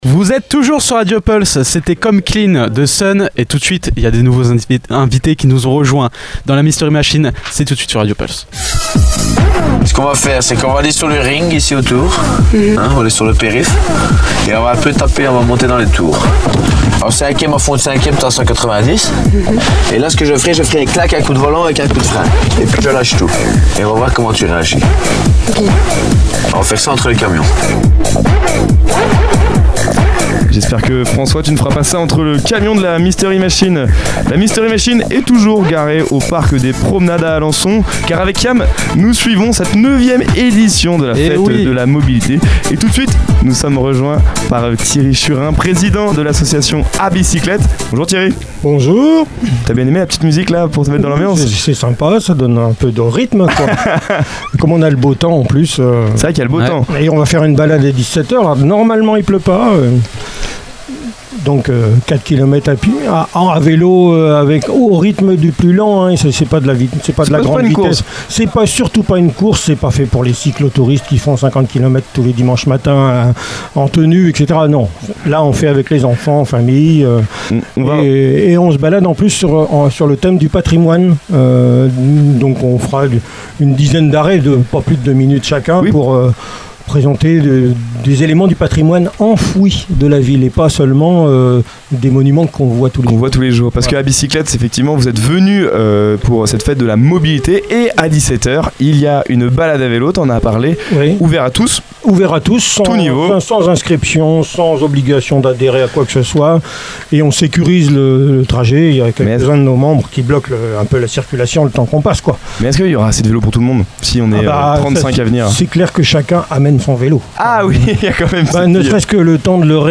À l’occasion de la Fête de la Mobilité au Parc des Promenades à Alençon, nous étions en direct depuis la Mystery Machine (studio radio aménagé dans une camionnette).